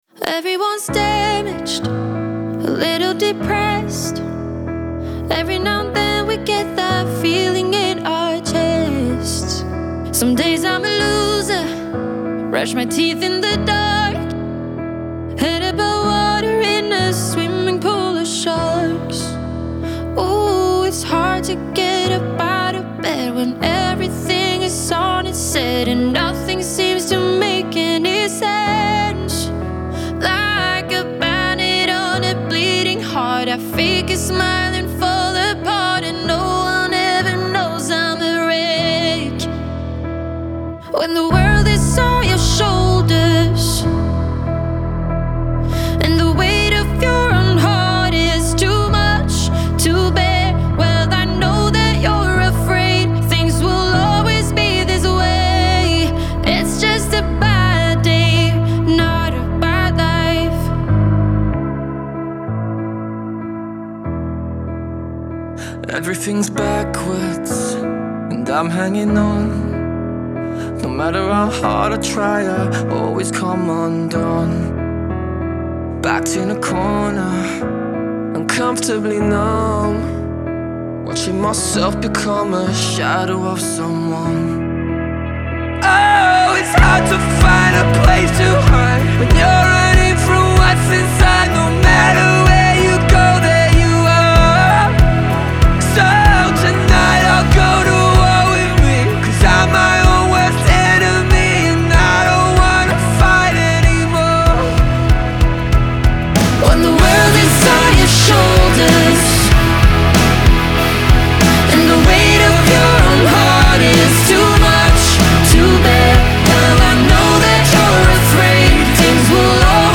Genre : Pop, Rock